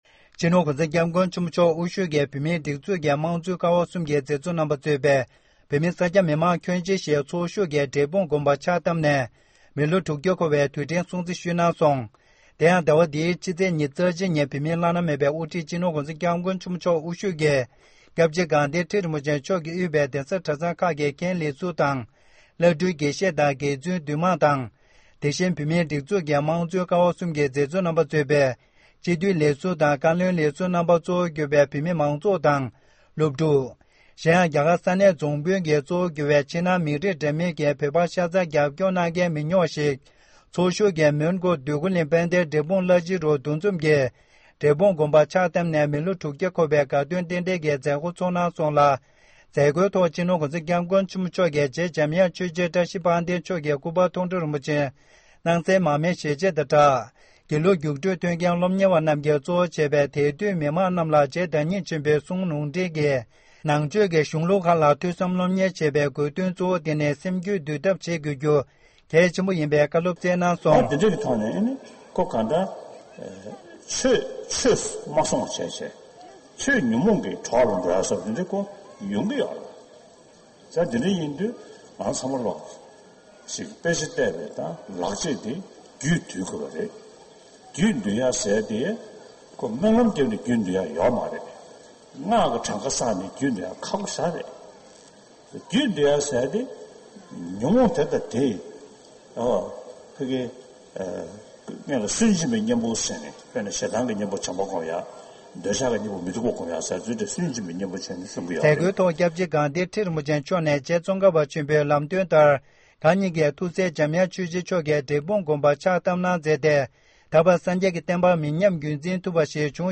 བོད་མི་ཚོས་རྒྱབ་མདུན་ངོ་ལྐོག་མེད་པའི་ཐོག་ནས་ཧུར་སྐྱེད་བྱེད་དགོས་བ་༧གོང་ས་མཆོག་གིས་གདན་ས་འབྲས་སྤུངས་ནས་གསུངས་འདུག